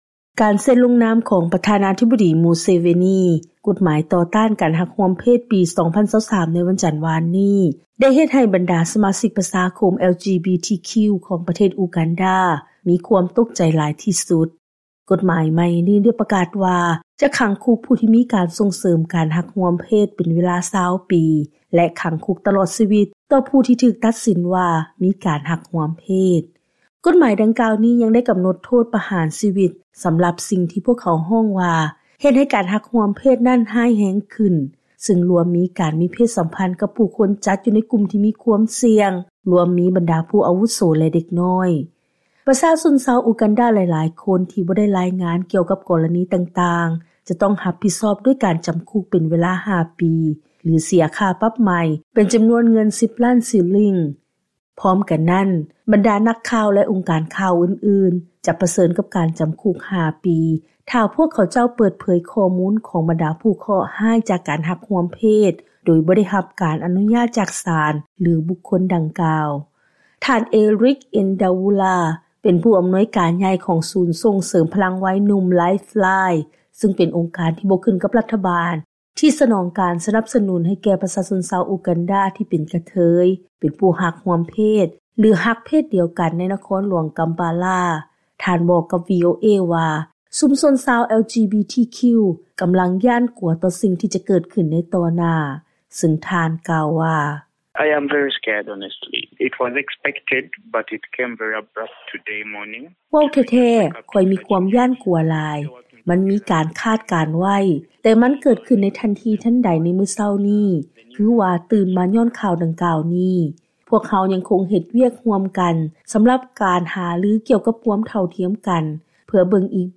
ເຊີນຮັບຟັງລາຍງານກ່ຽວກັບ ການປະນາມຕໍ່ການລົງນາມກົດໝາຍຕໍ່ຕ້ານການຮັກຮ່ວມເພດໃນ ອູການດາ ຂອງບັນດານັກເຄື່ອນໄຫວ